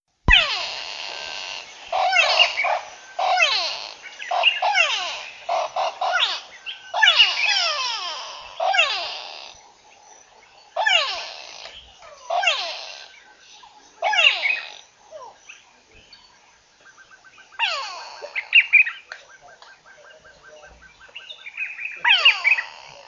Relax Free sound effects and audio clips
• comical bird in savannah.wav
comical_bird_in_savannah_3PH.wav